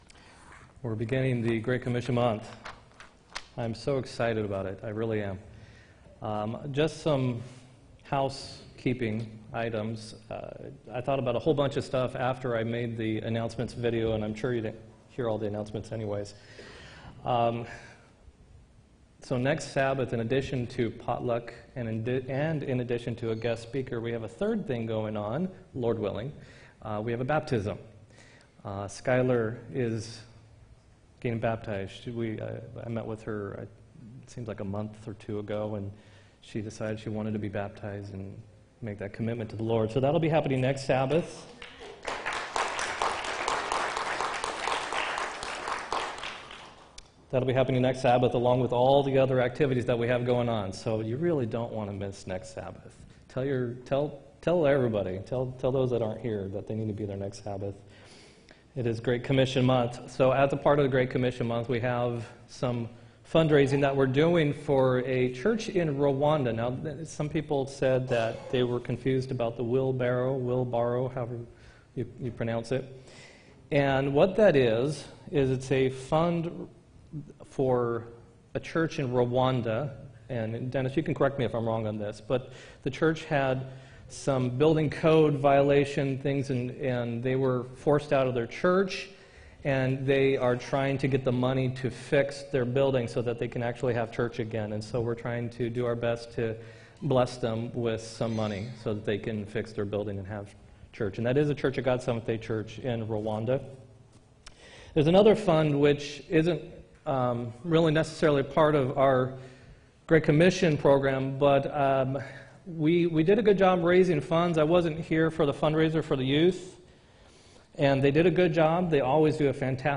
10-6-18 sermon
10-6-18-sermon.m4a